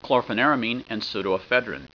Pronunciation
(klor fen IR a meen & soo doe e FED rin)